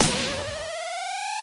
sniper_shot_02.ogg